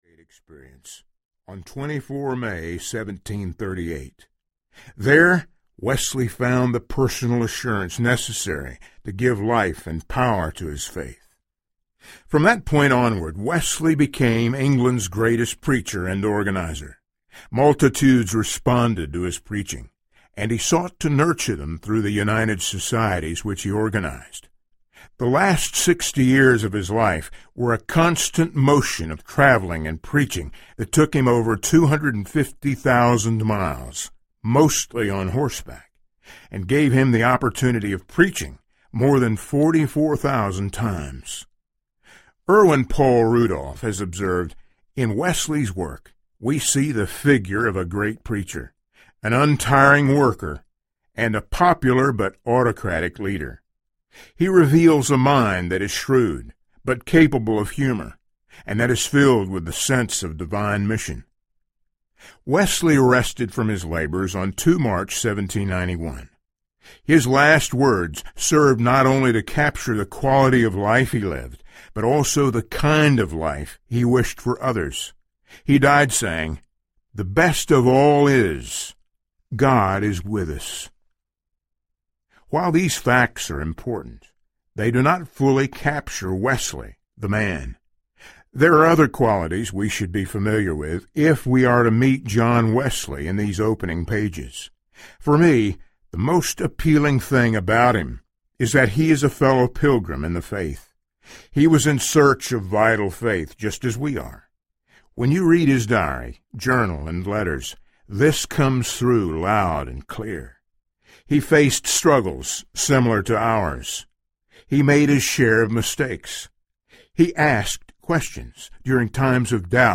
The Way to Heaven Audiobook
Narrator
3.75 Hrs. – Unabridged